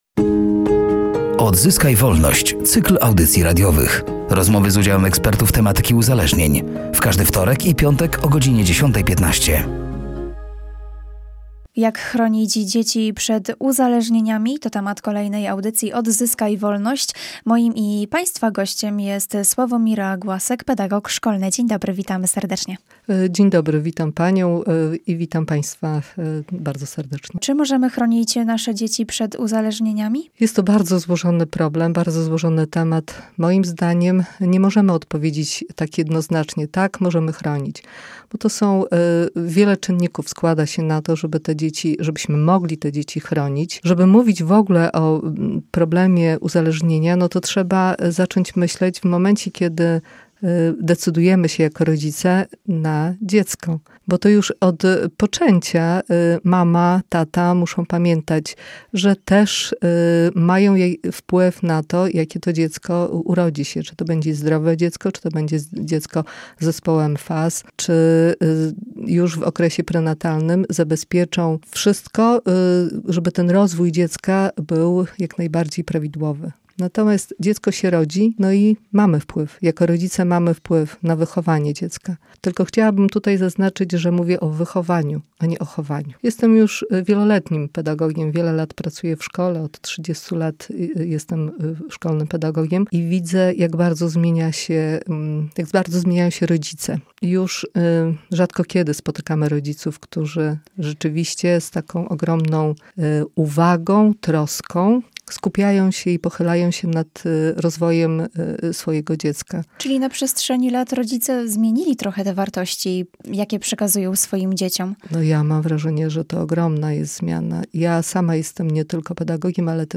„Odzyskaj Wolność”, to cykl audycji radiowych poświęconych profilaktyce uzależnień wśród dzieci i młodzieży.